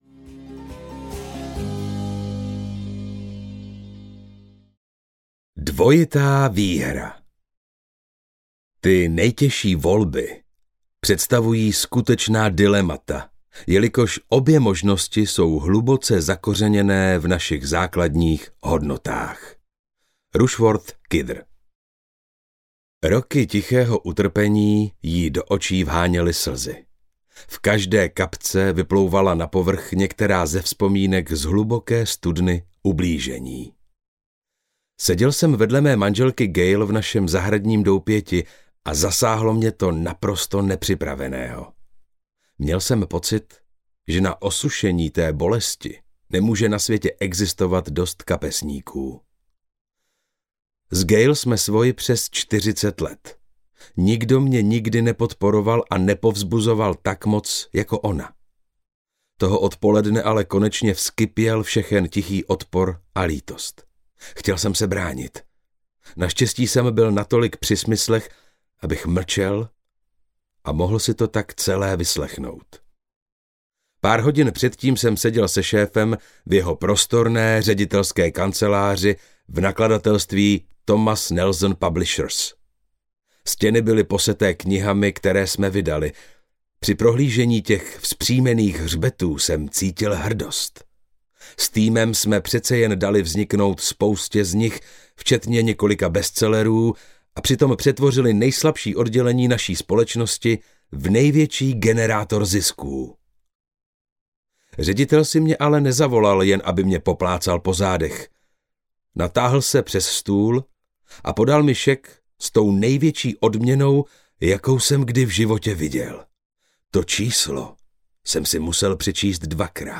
Dvojitá výhra audiokniha
Ukázka z knihy